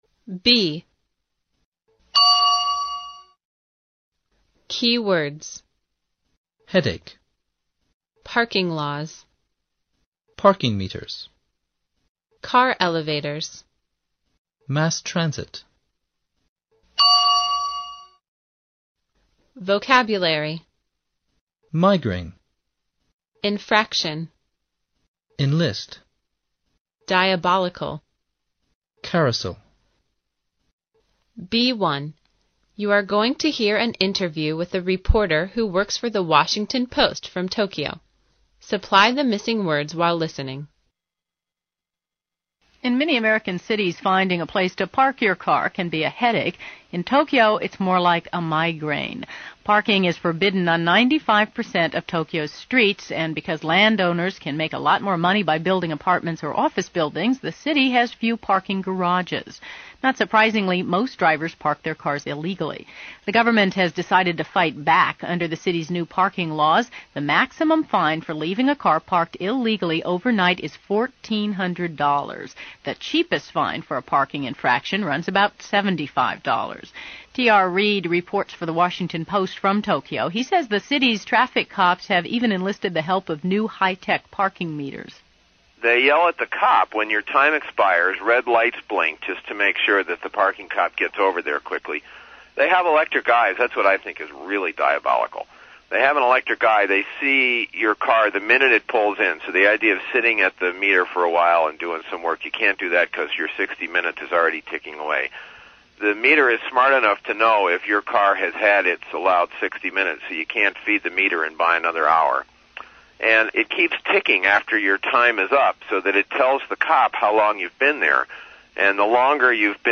B1. You're going to hear an interview with a reporter who works for the Washington Post from Tokyo, supply the missing words while listening.